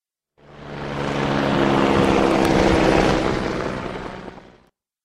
helicopter